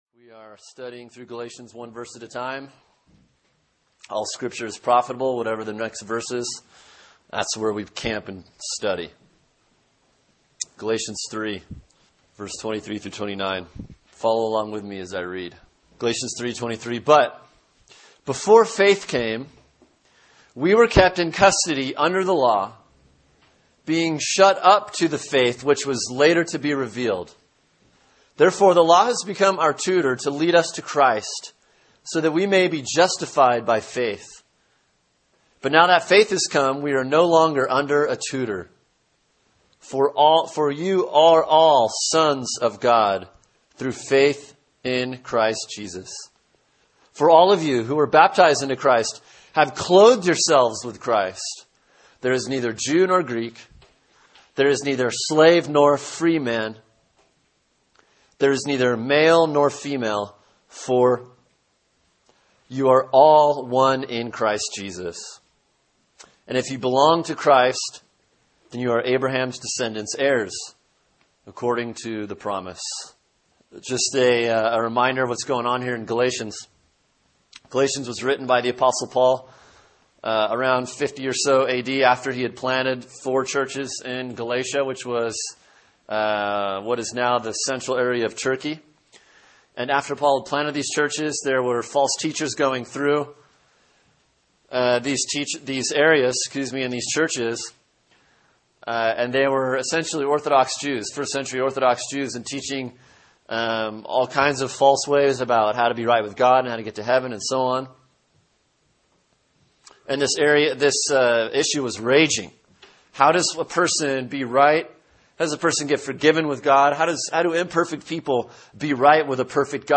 Sermon: Galatians 3:23-29 “When God Keeps a Promise” Part 2 | Cornerstone Church - Jackson Hole